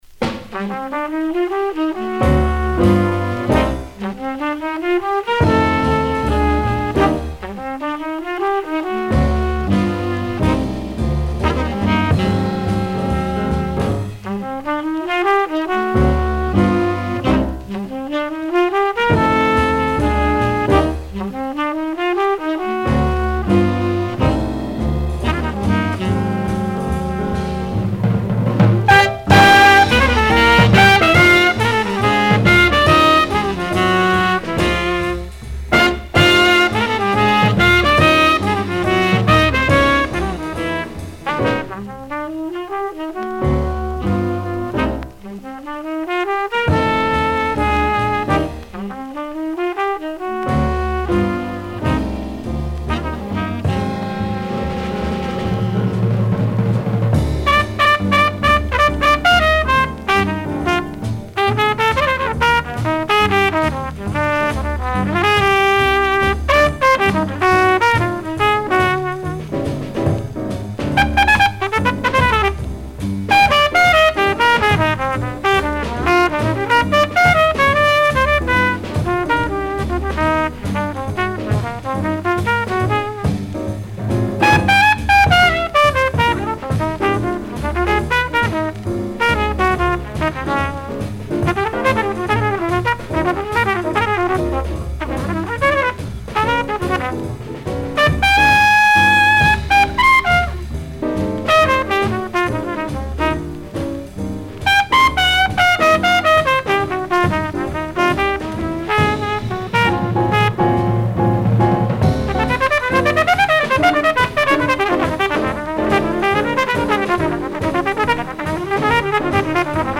Original 10 inch pressing